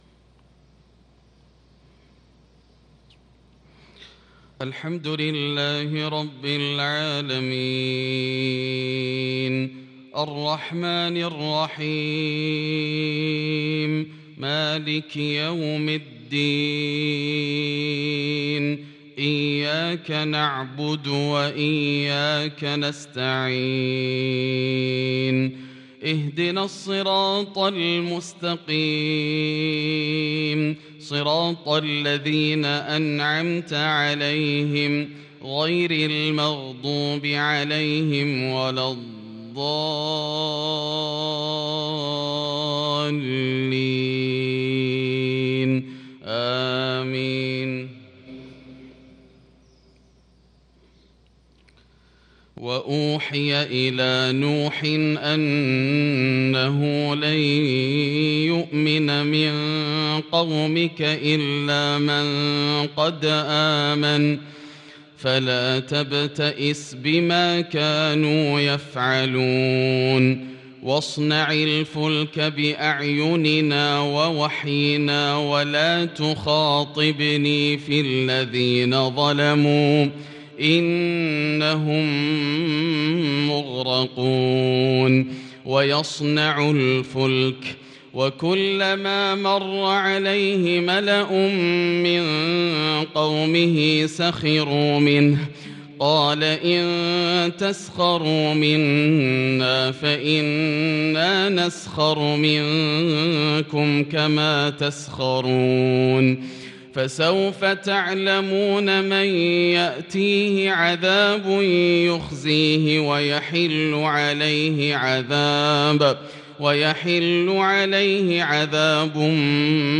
صلاة الفجر للقارئ ياسر الدوسري 5 جمادي الأول 1443 هـ
تِلَاوَات الْحَرَمَيْن .